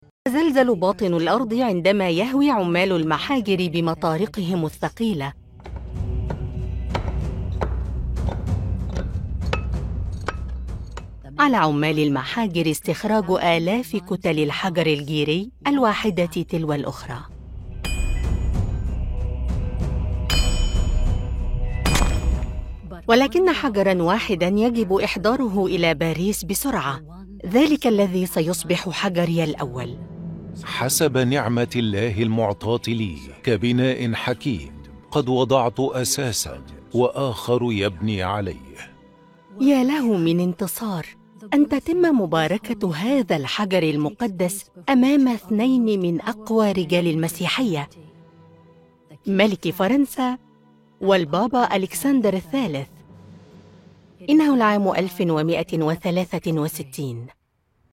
E-learning
Micrófono: Rode NT1-A
Estudio: Estudio casero con tratamiento profesional para una acústica óptima